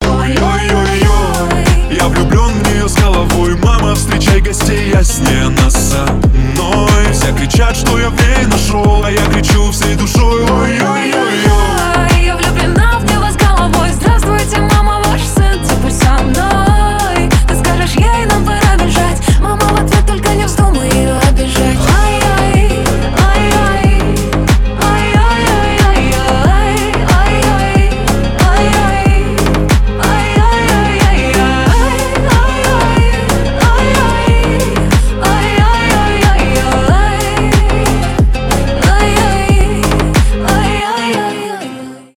танцевальные
поп